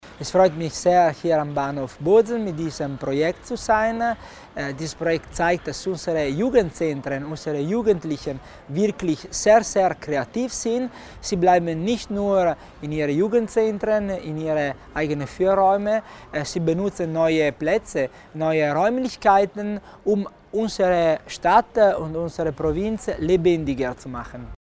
Landesrat Christian Tommasini erläutert das Projekt Temporary playroom and exhibition